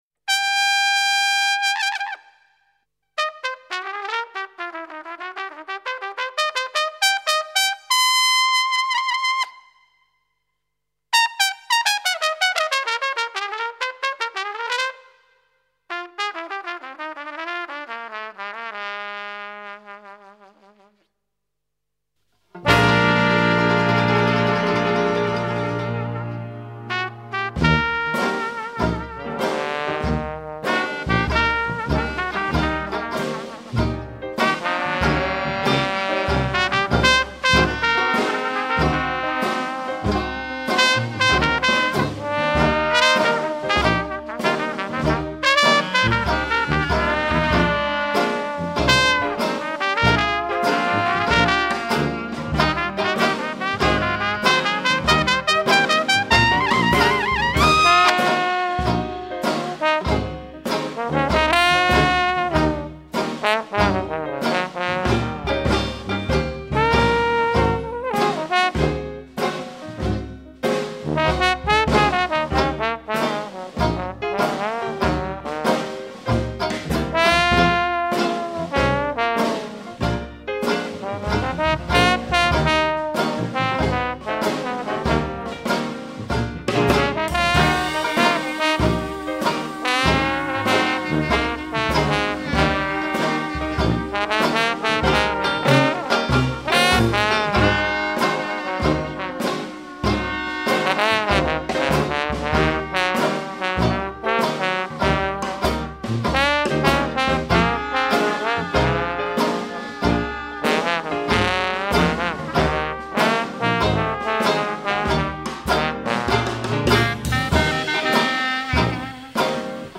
batterie
trombone
clarinette
piano
banjo
contrebasse
sousaphone